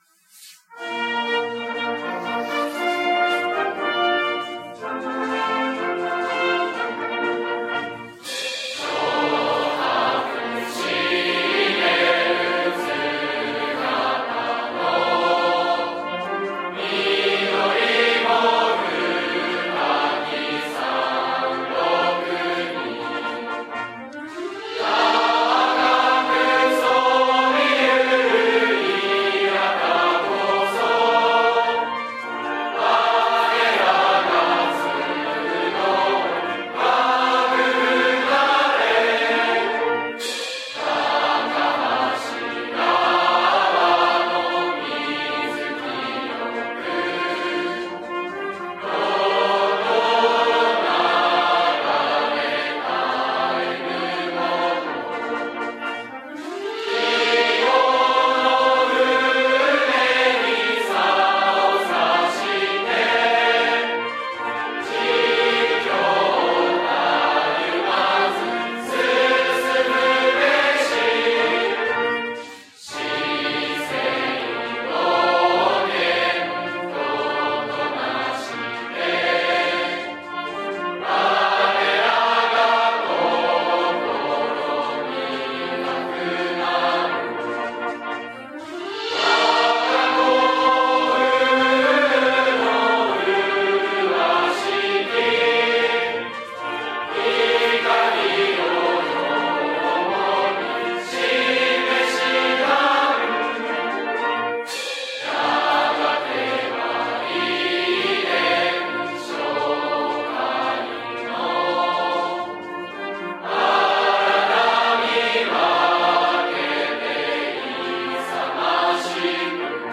校歌